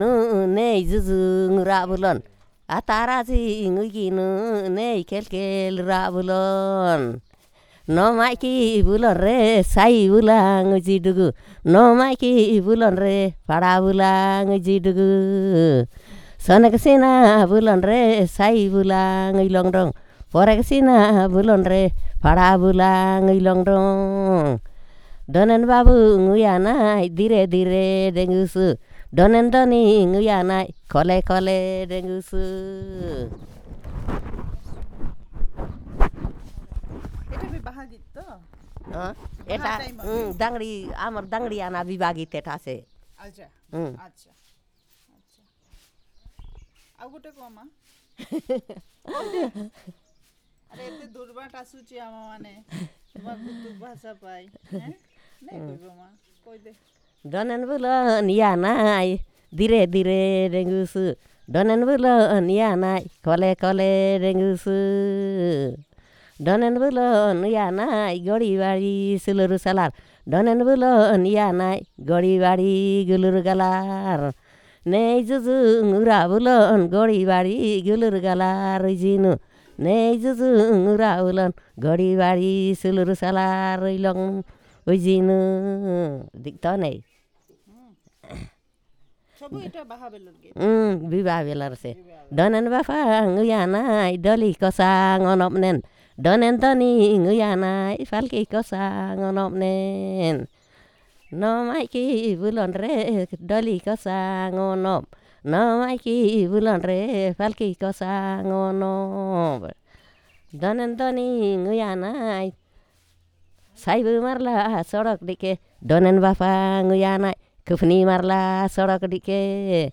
Perfomance of folk song